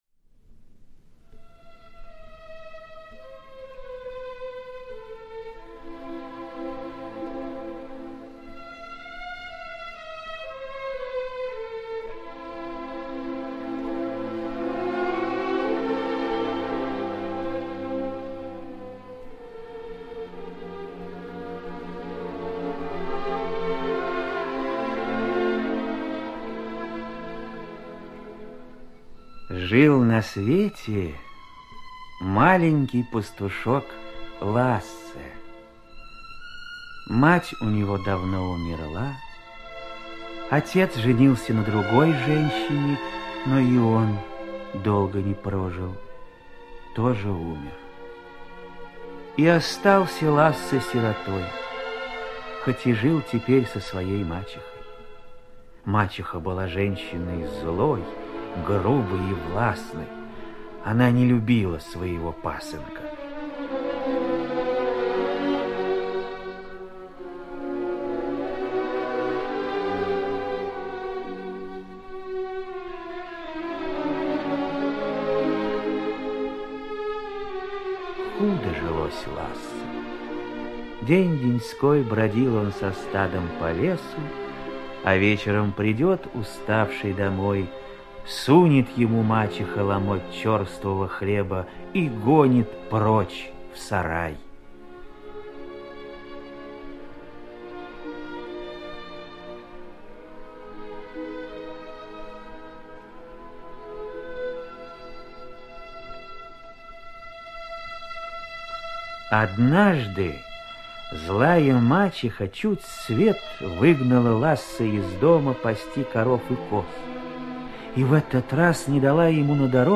Пастушок и король эльфов - шведская аудиосказка - слушать онлайн